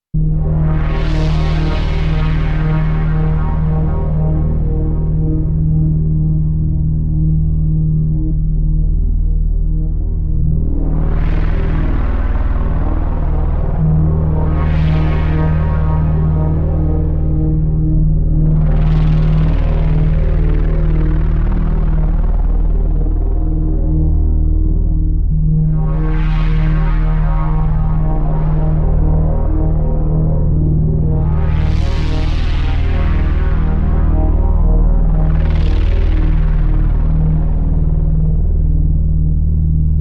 Hydrasynth > CXM 1978 > TwinTrak Pro Compressor > BBE 882i > Heat (Enhancement Circuit)